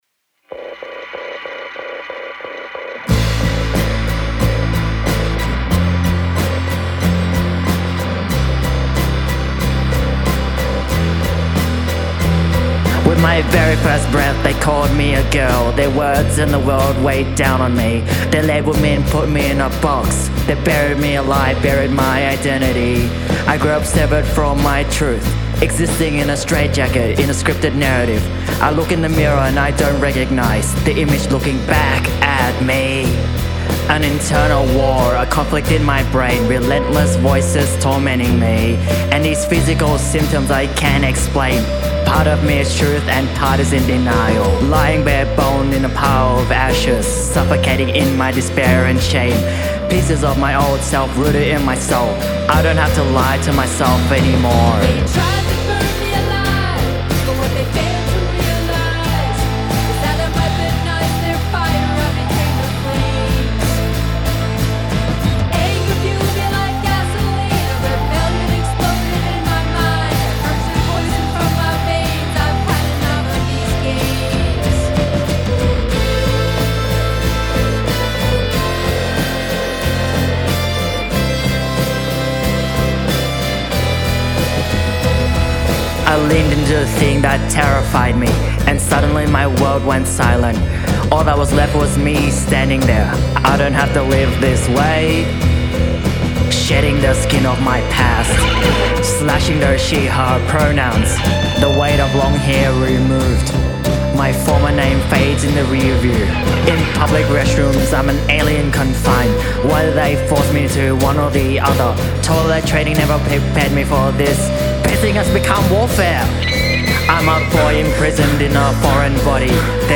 lead vocals
harmonies and background vocals
bass
electric guitar
drums, piano, synth